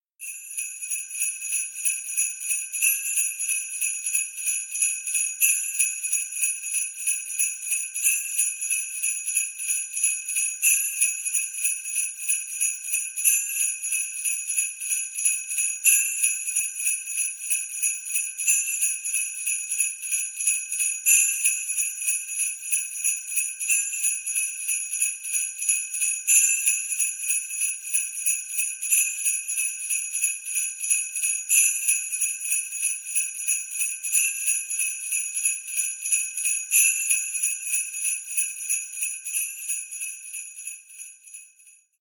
جلوه های صوتی
دانلود صدای زنگوله 4 از ساعد نیوز با لینک مستقیم و کیفیت بالا